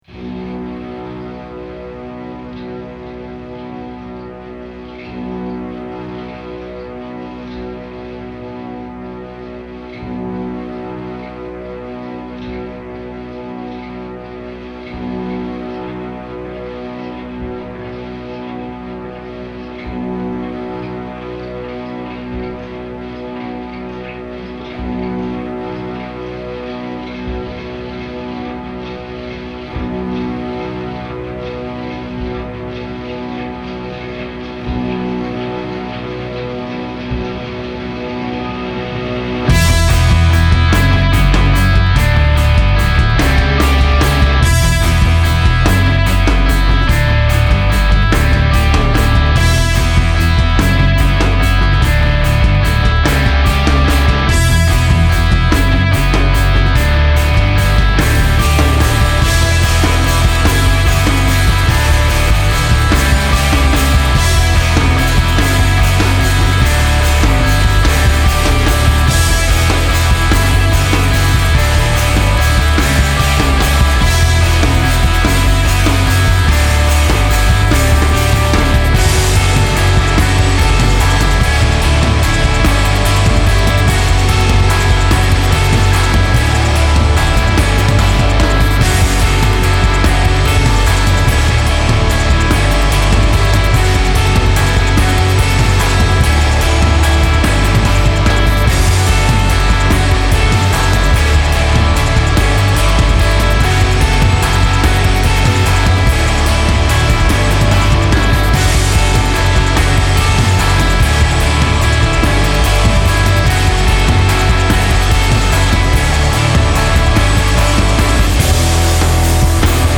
Dans un registre post rock
production particulièrement puissante